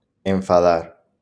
IPA[ẽɱ.fa.ˈðaɾ]